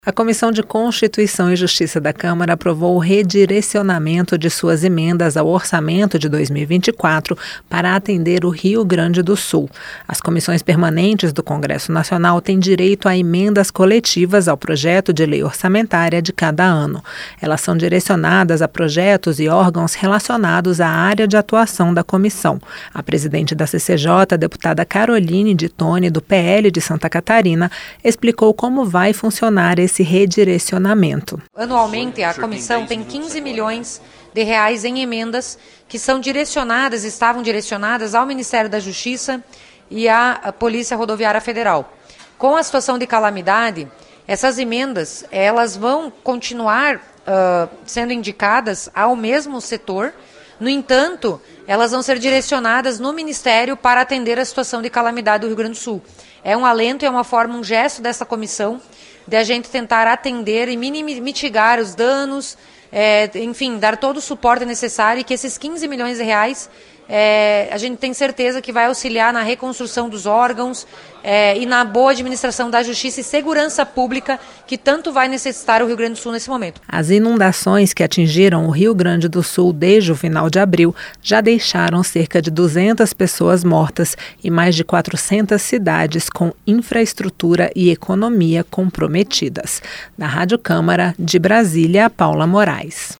COMISSAO DE CONSTITUIÇÃO E JUSTIÇA DESTINA EMENDAS PARA SOCORRER O RIO GRANDE DO SUL DIANTE DA TRAGÉDIA CLIMÁTICA. A REPORTAGEM